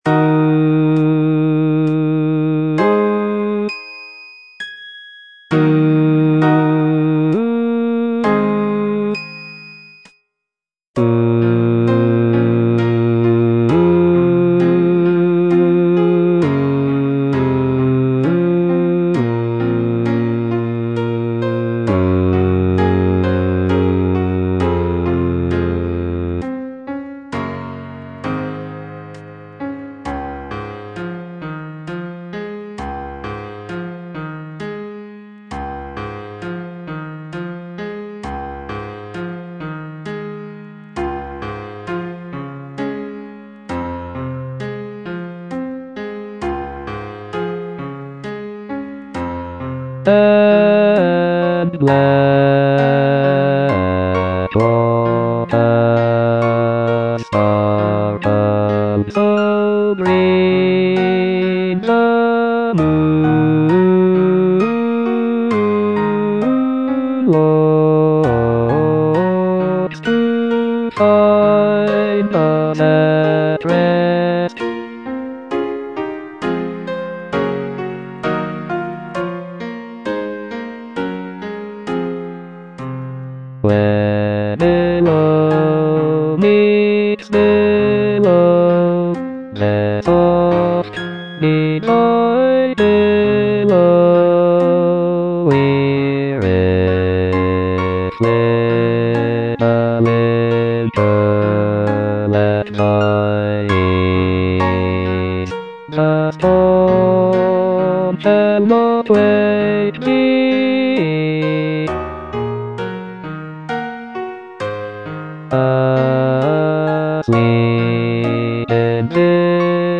Bass II (Voice with metronome)
choral work